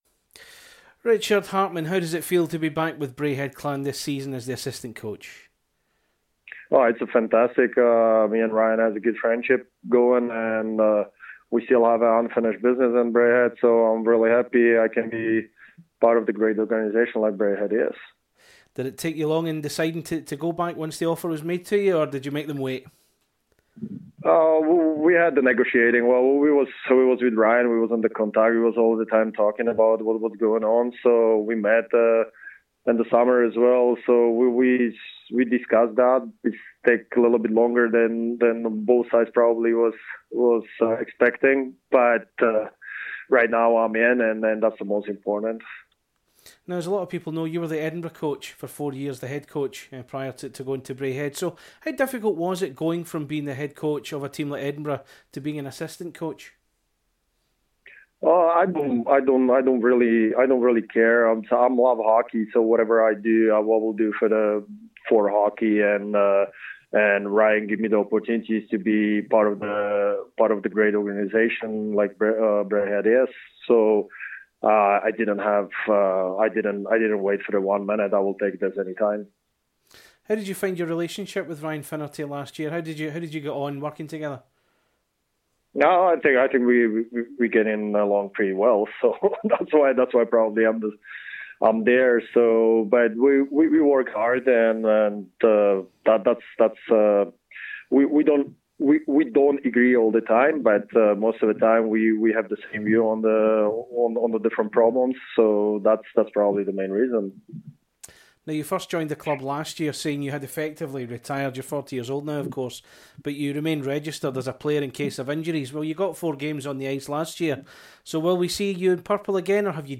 Clan Chat / INTERVIEW